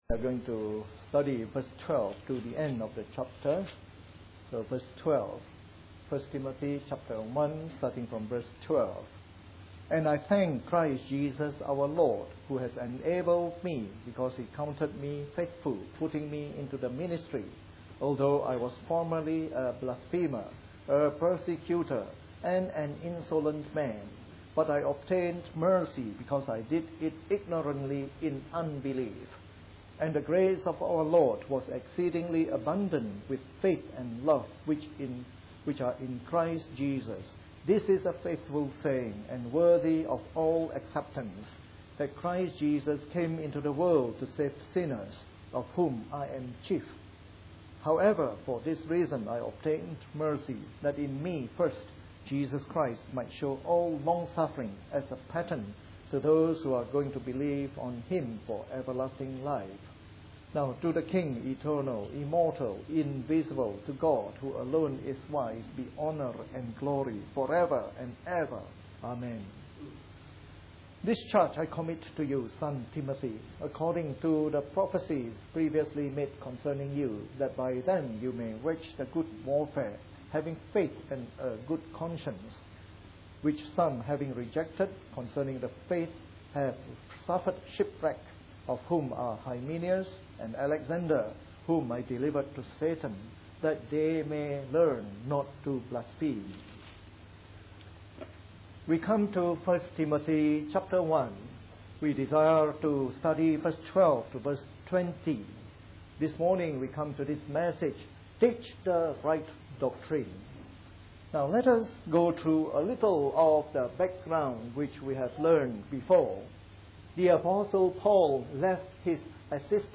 A sermon in the morning service from our new series on 1 Timothy.